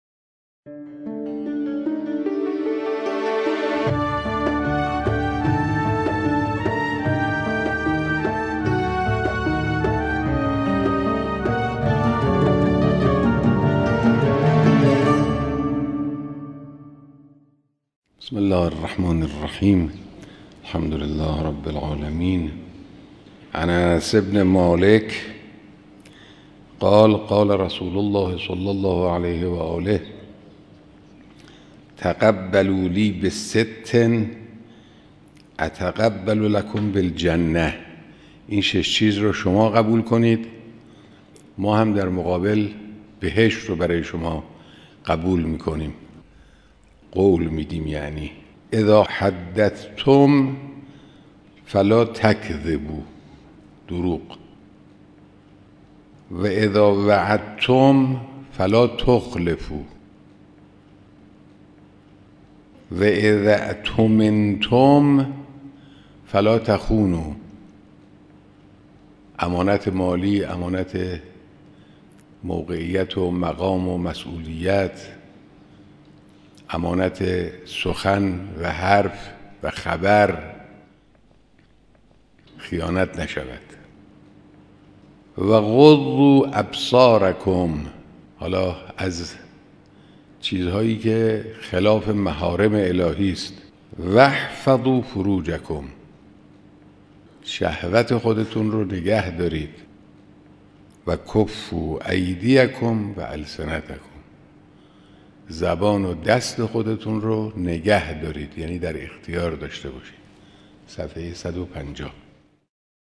شرح احادیث اخلاقی توسط رهبر/ تضمین بهشت